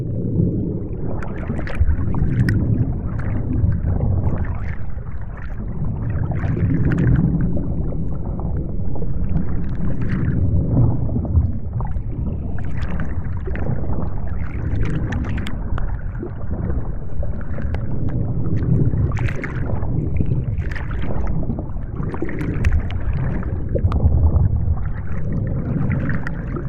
Underwater_Audio.wav